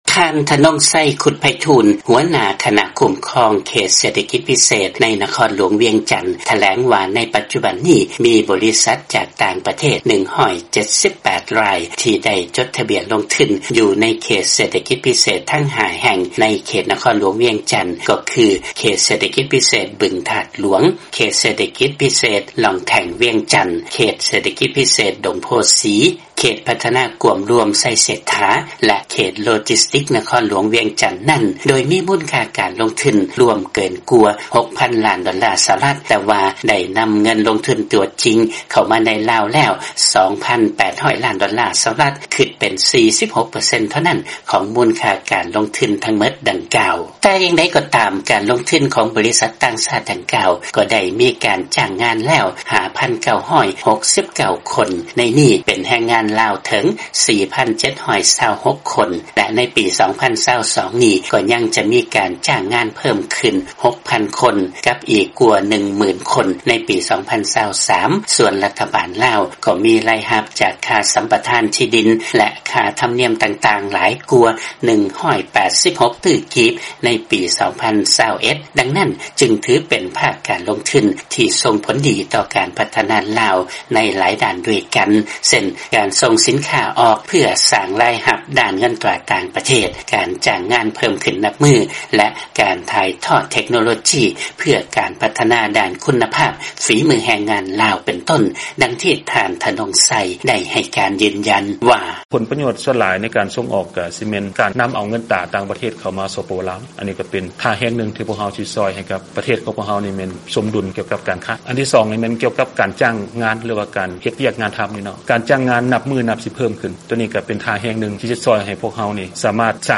ເຊີນຟັງລາຍງານ ເຂດເສດຖະກິດພິເສດທັງ 5 ແຫ່ງໃນເຂດນະຄອນວຽງຈັນ ໄດ້ຮັບເງິນລົງທຶນຈາກຕ່າງປະເທດ 2,800 ລ້ານໂດລາ ແລ້ວ